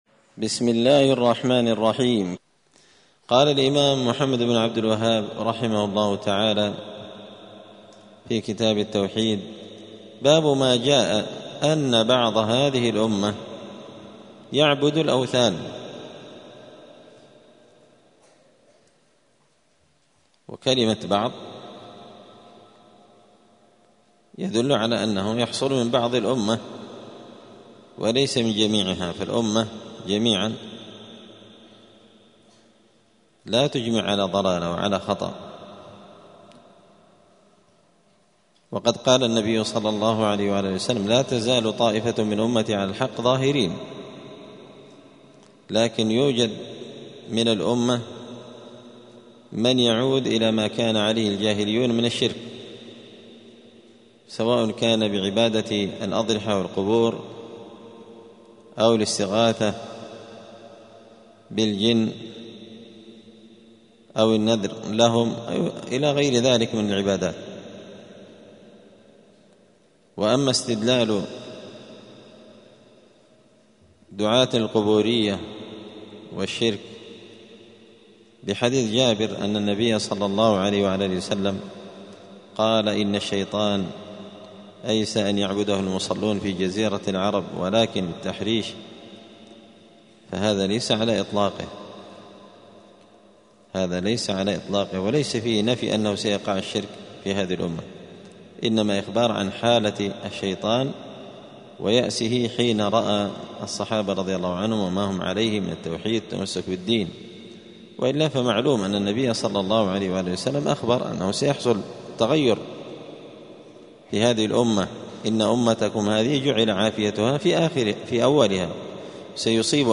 دار الحديث السلفية بمسجد الفرقان قشن المهرة اليمن
*الدرس الثالث والستون (63) {باب ماجاء أن بعض هذه الأمة يعبد الأوثان}*